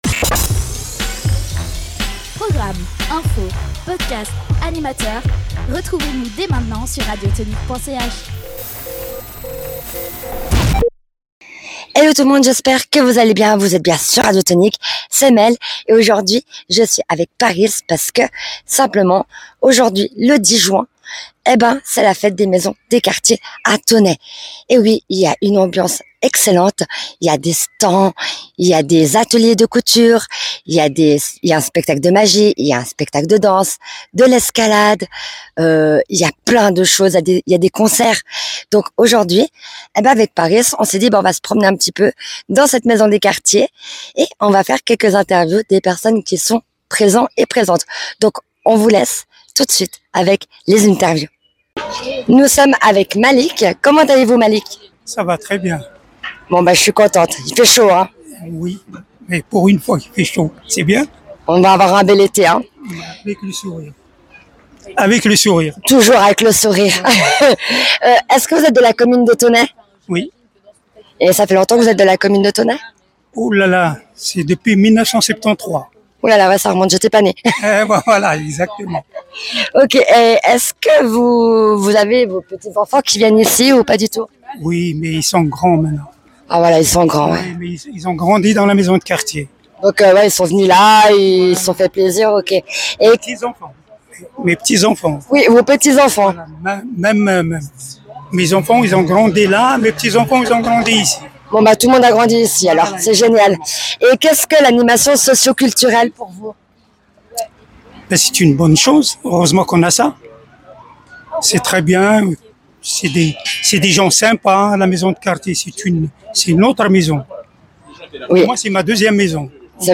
Interviews
Voici quelques interviews de personnes présentes pour cette belle journée ensoleillée.
fête-des-Maison-de-quartier-de-Thônex.mp3